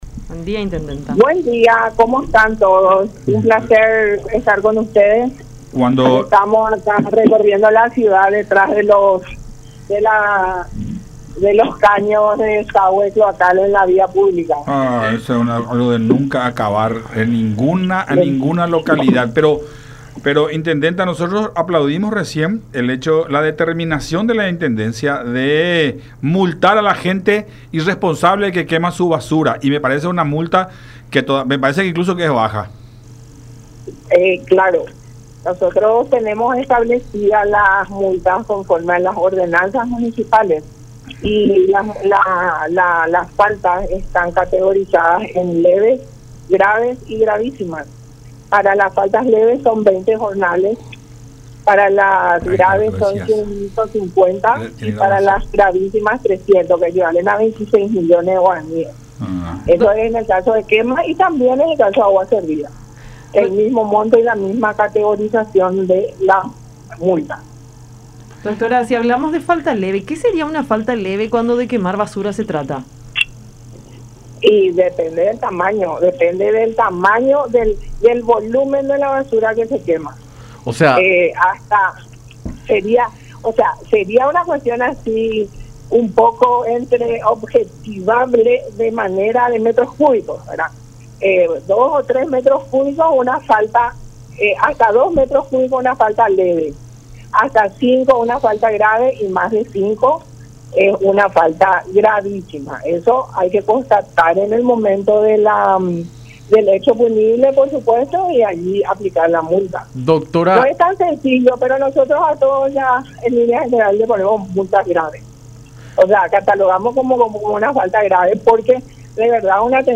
Nilsa Sánchez, intendenta de Fernando de la Mora.